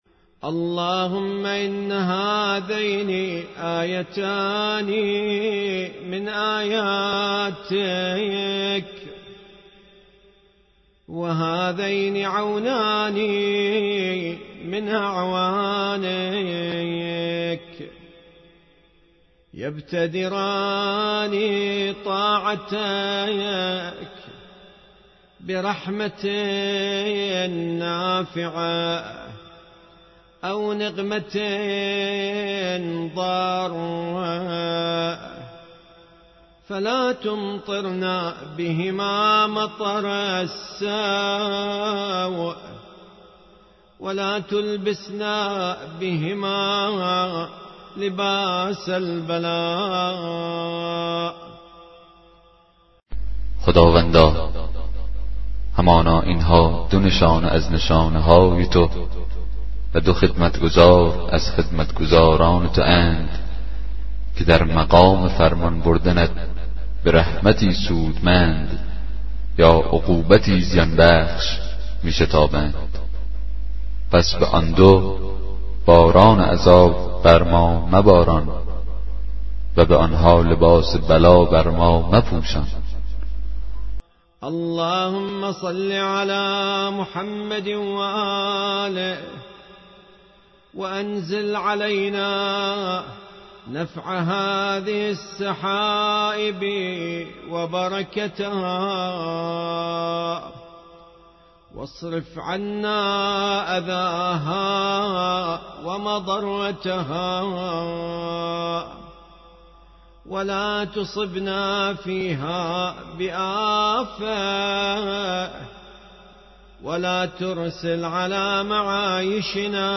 کتاب صوتی دعای 36 صحیفه سجادیه